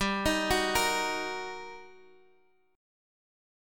GmM7#5 Chord